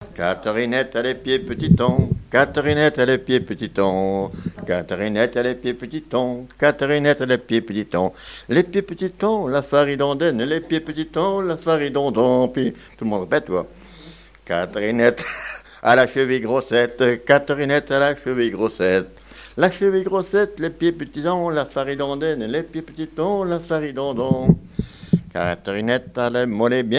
Mémoires et Patrimoines vivants - RaddO est une base de données d'archives iconographiques et sonores.
Genre énumérative
danses à l'accordéon diatonique et chansons
Pièce musicale inédite